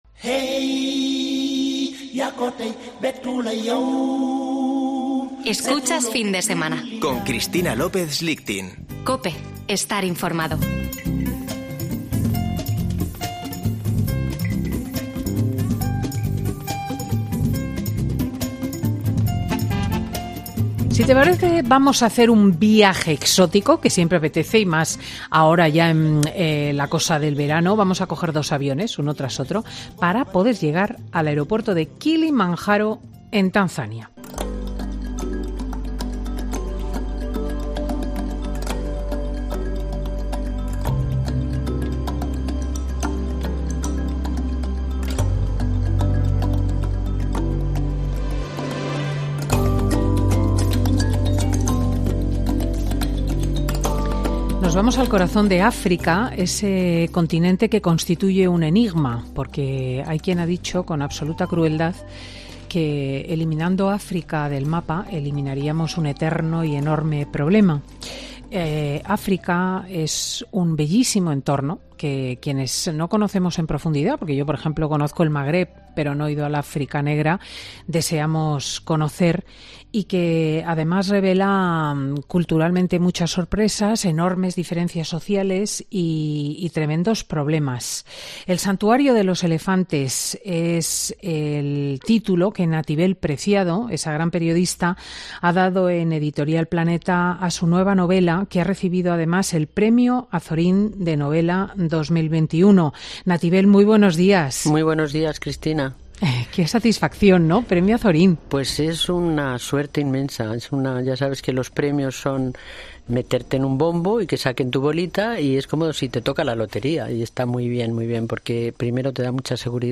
Nativel Preciado, escritora y autora de 'El santuario de los elefantes', pasa por Fin de Semana con Cristina para presentar su nueva novela